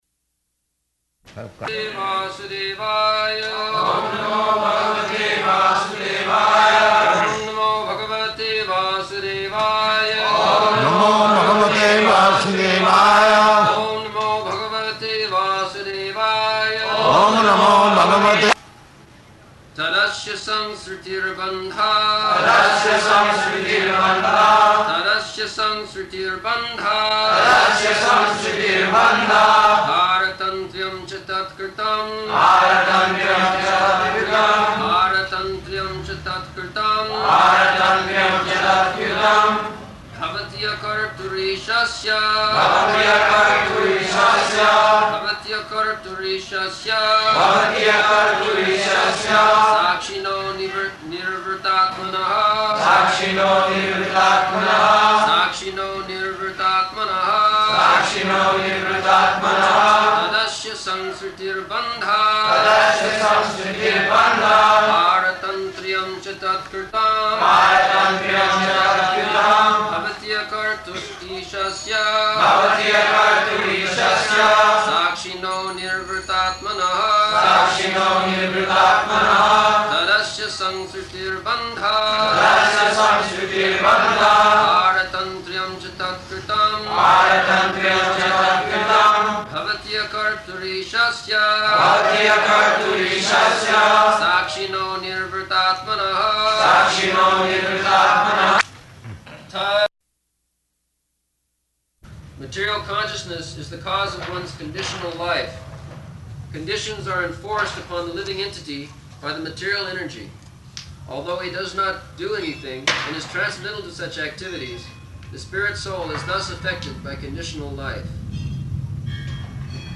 December 19th 1974 Location: Bombay Audio file
[Prabhupāda and devotees repeat] [leads chanting of verse, etc.]